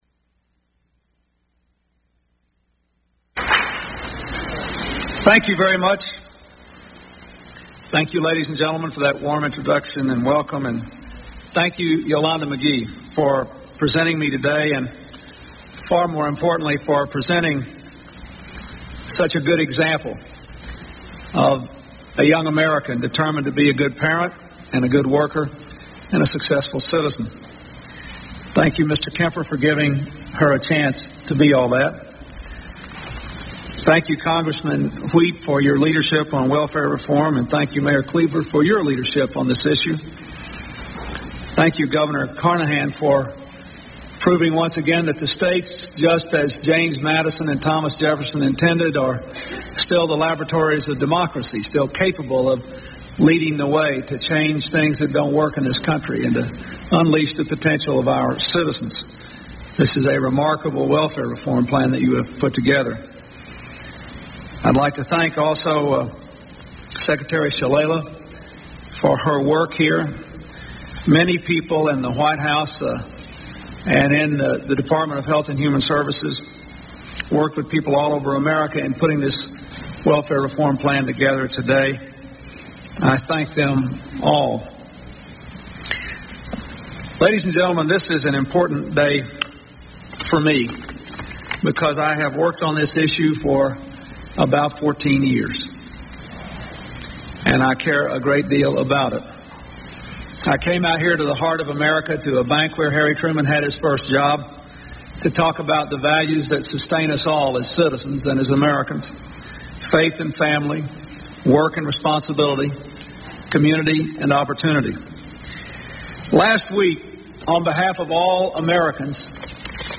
U.S. President Bill Clinton introduces his welfare reform package in a meeting in Kansas City, MO